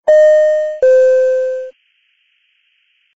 fasten-seat-belt_24688.mp3